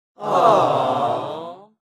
aww-sound_cG4gI2D.mp3